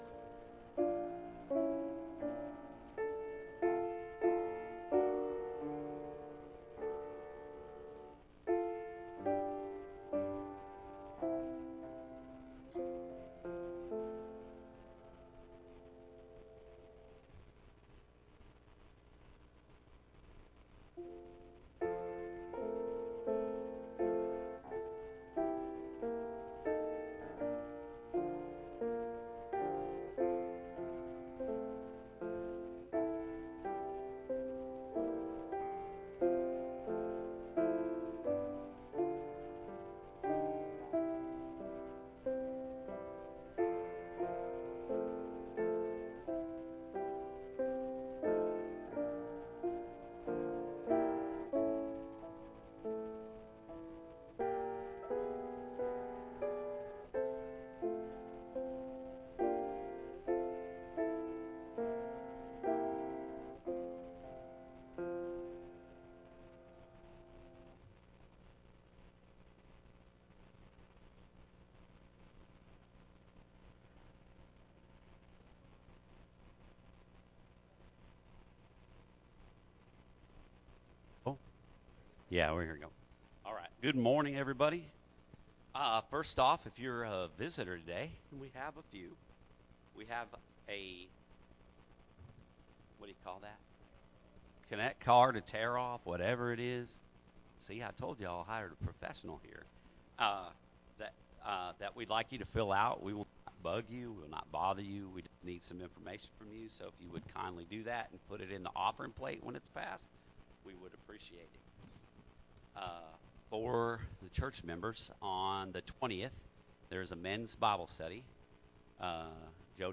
Minor Prophets - Major Lessons Sunday Morning Sermon